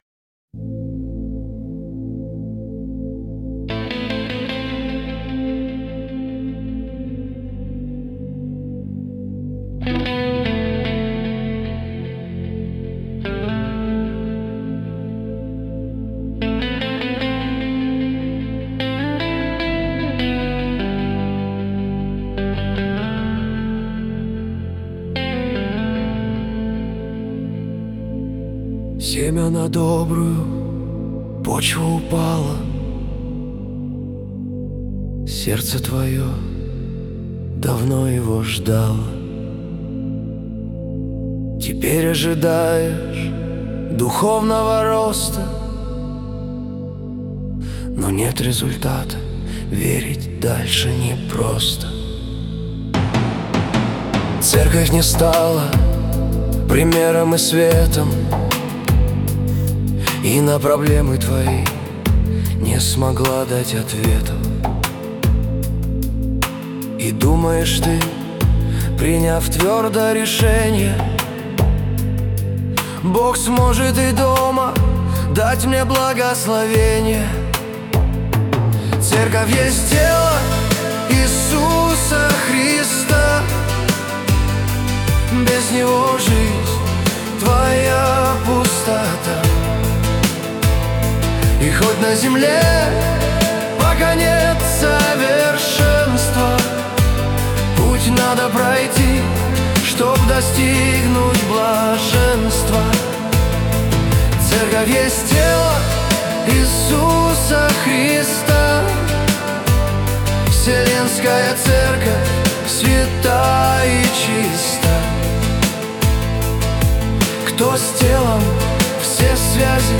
177 просмотров 543 прослушивания 80 скачиваний BPM: 76